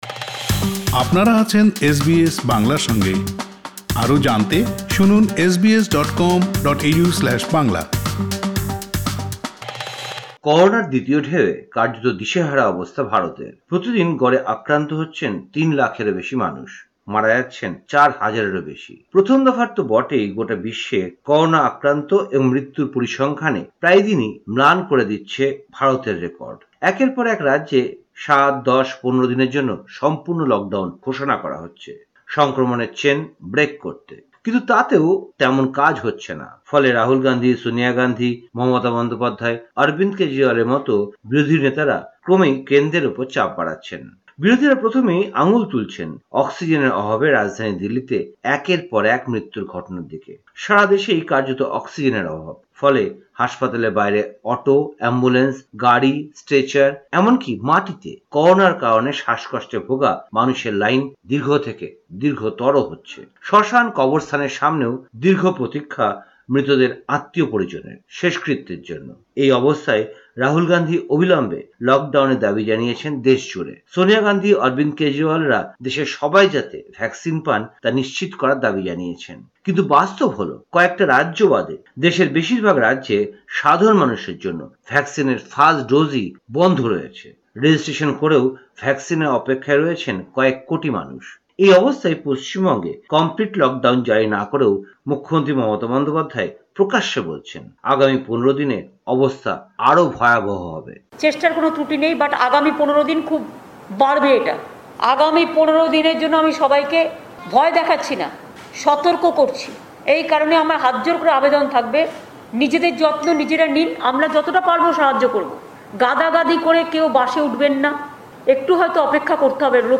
ভারতীয় সংবাদ: ১০ মে ২০২১